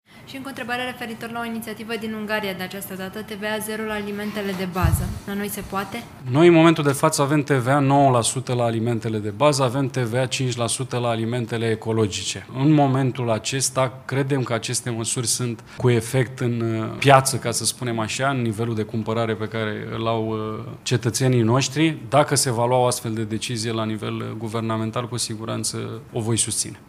Despre măsurile luate în Ungaria și despre recentele propuneri din țara vecină a fost întrebat și ministrul Agriculturii, după ședința de Guvern de joi.
Cât despre o reducere a TVA și mai mult, poate chiar la zero pentru alimentele de bază, cum s-a propus în Ungaria, ministrul Agriculturii, Adrian Chesnoiu, a spus că ar fi de accord cu o asemenea idee dar că la noi nu a făcut nimeni nicio propunere: